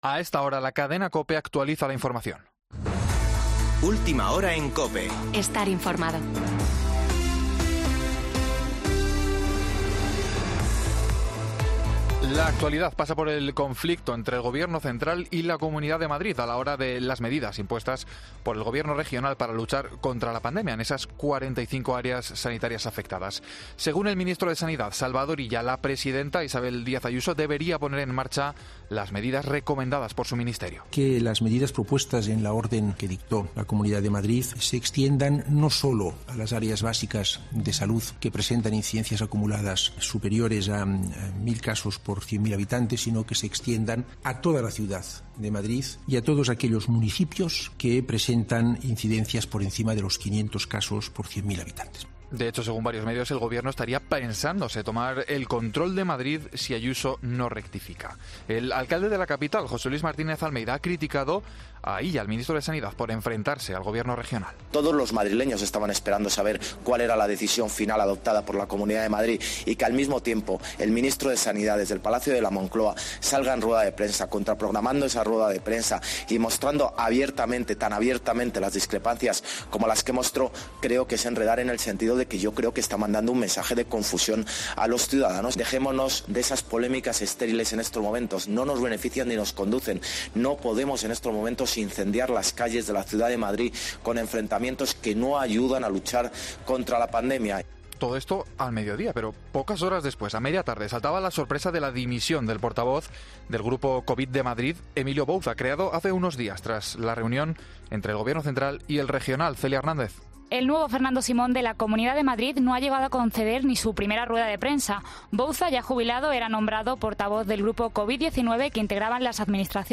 Boletín de noticias de COPE del 26 de septiembre de 2020 a las 19.00 horas